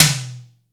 BIG TOM.wav